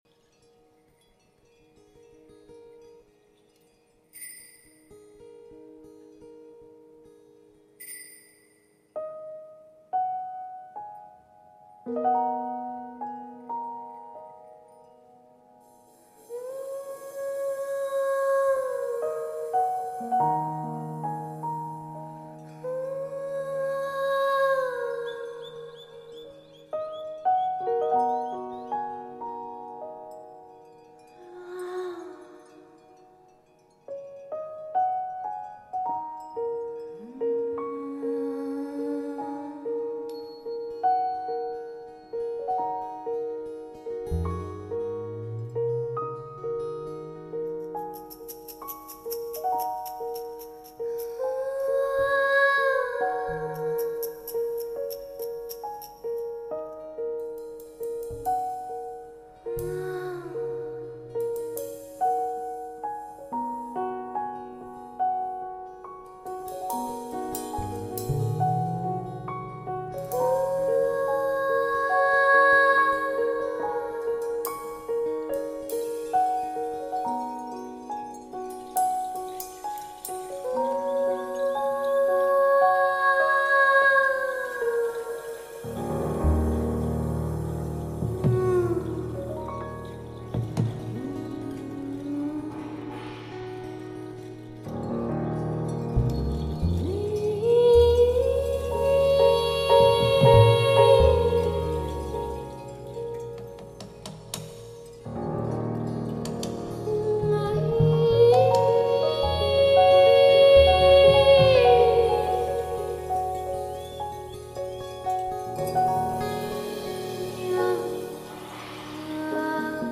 那种犹如录音室中的强大效果，和CD相比有过之而无不及。
人声就是最好的乐器